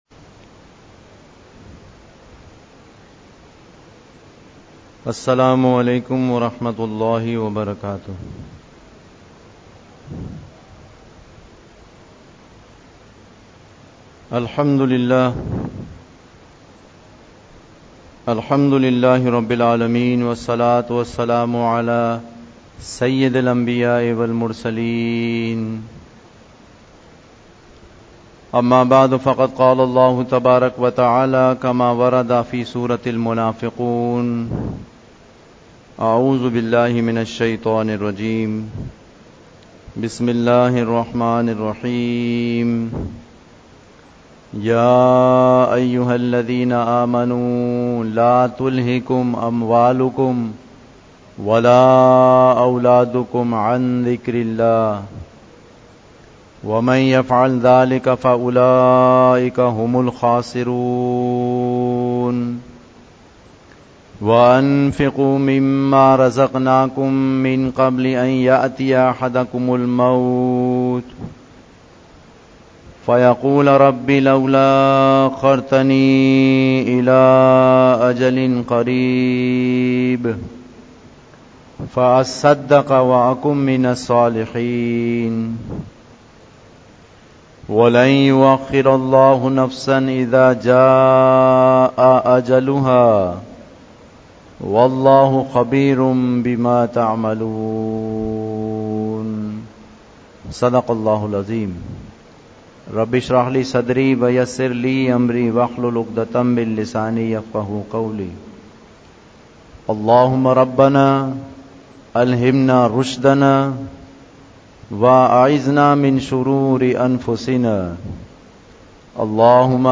Khutbat-e-Jummah (Friday Sermons)
at Masjid Al-Furqan, Karachi University Society, Gulzar-e-Hijri, Karachi, Pakistan